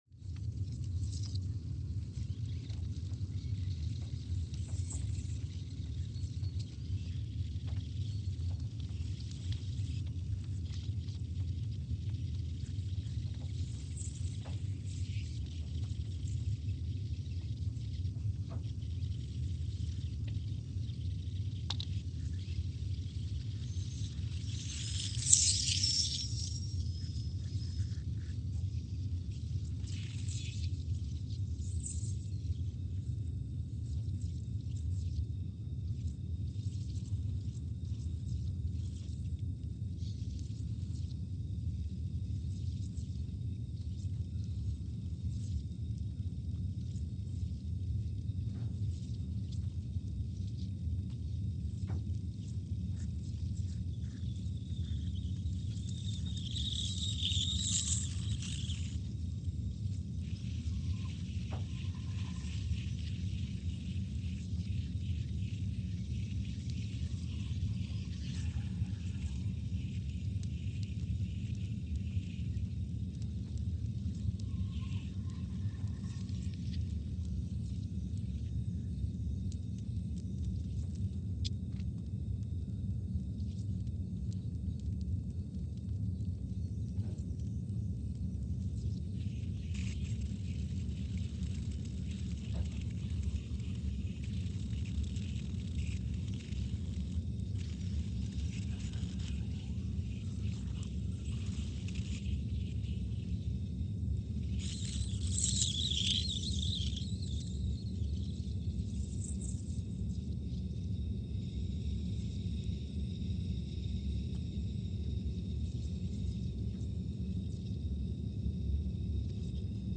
Scott Base, Antarctica (seismic) archived on January 14, 2020
No events.
Sensor : CMG3-T
Speedup : ×500 (transposed up about 9 octaves)
Loop duration (audio) : 05:45 (stereo)
Gain correction : 25dB